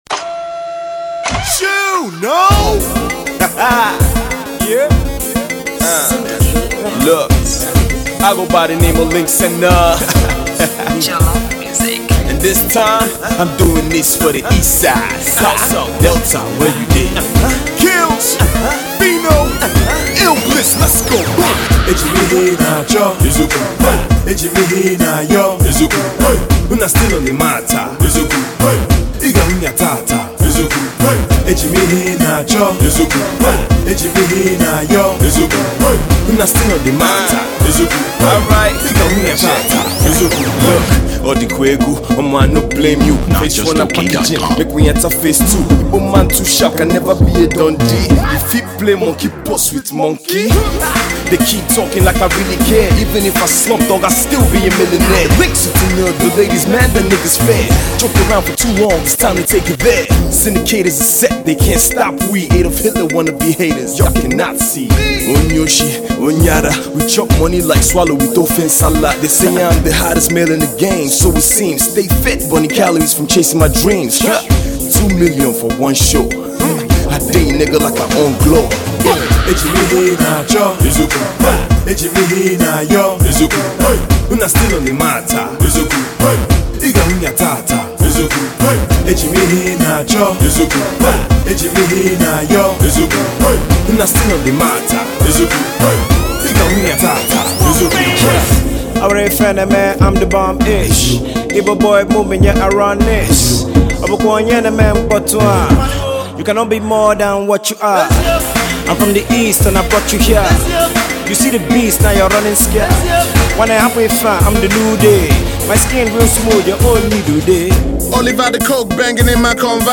Igbo street anthem